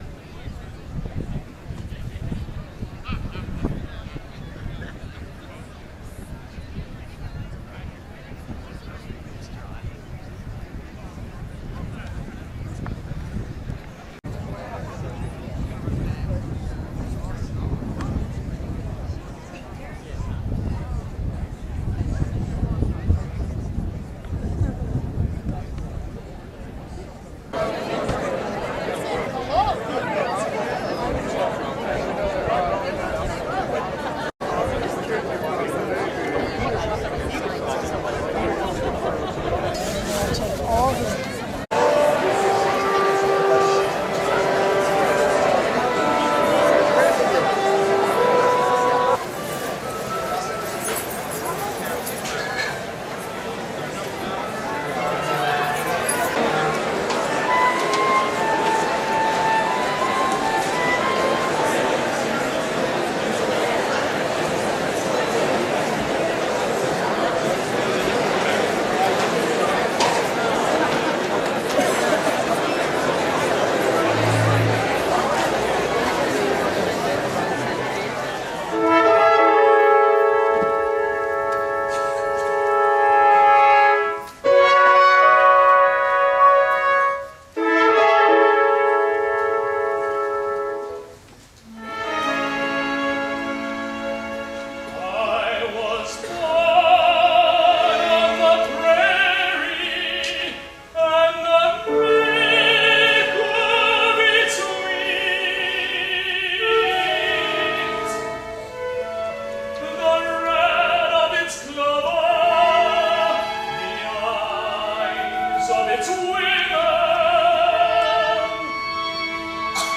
Choral Society Concert at Channing Daughters amidst some unusual but stunning wooden oversized sculptures - a personal favorite is the pencil.
choral_society.rm